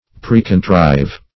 Precontrive \Pre`con*trive"\, v. t. & i. To contrive or plan beforehand.